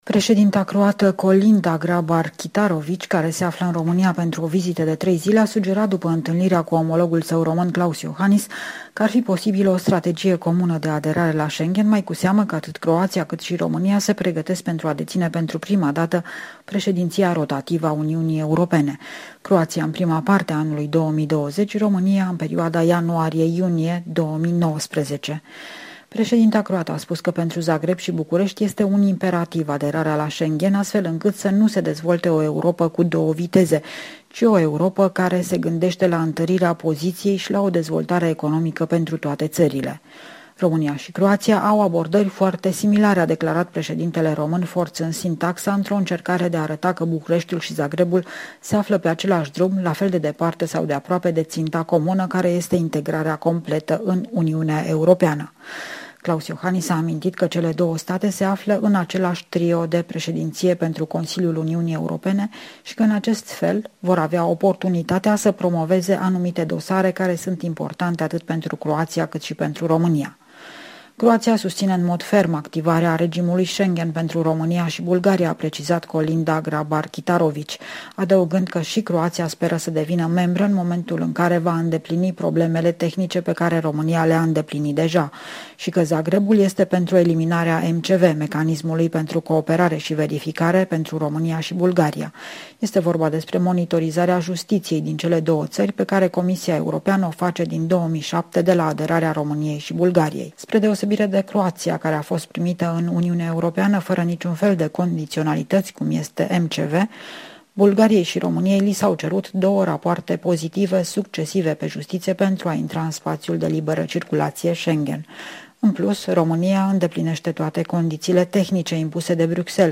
Corespondența zilei de la București